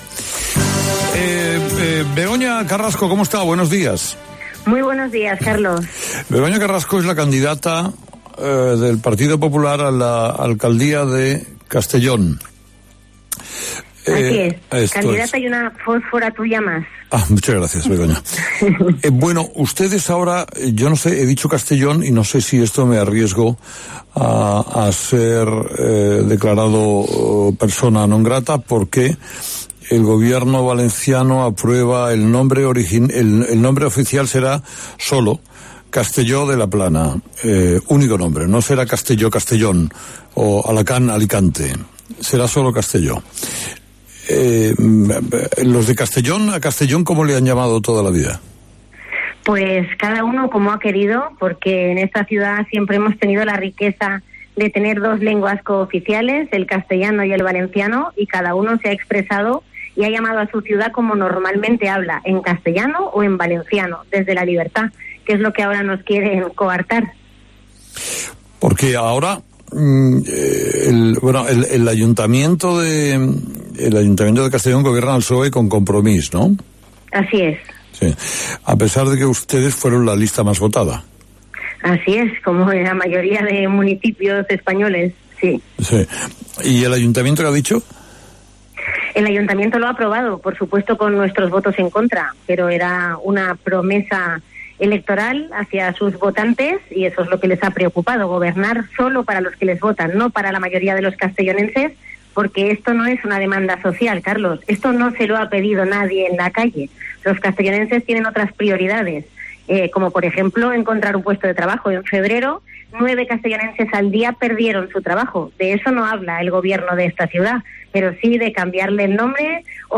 Begoña Carrasco se ha comprometido en la entrevista mantenida con Carlos Herrera a que, si tras las elecciones municipales del mes de mayo logra convertirse en alcaldesa, “la primera medida que tomaré, por supuesto será preocuparme por el empleo de los castellonenses, pero como medida simbólica será devolverle la libertad a esta ciudad y que los castellonenses puedan llamarla Castellón o Castelló, como siempre han hecho”.